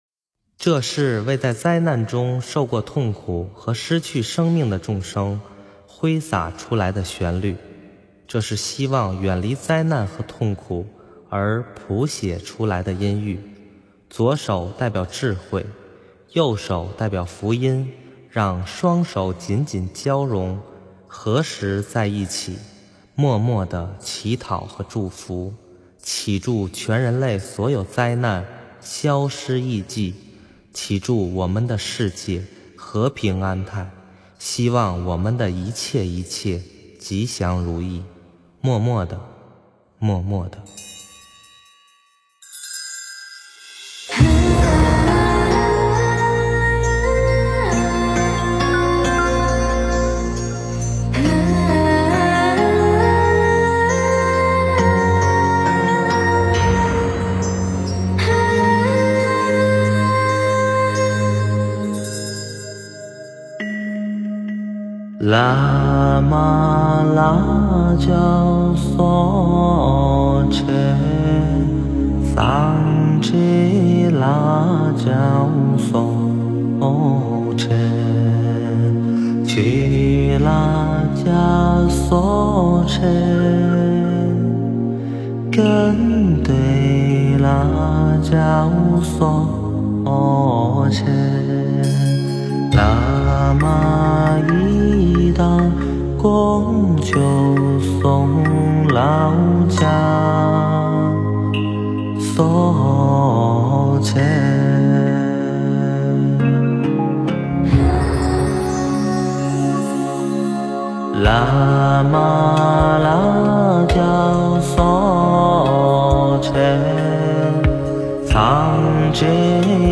诵经
佛音 诵经 佛教音乐 返回列表 上一篇： 供养请 下一篇： 祈愿颂 相关文章 夫妻与因果--有声佛书 夫妻与因果--有声佛书...